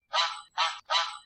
duck.ogg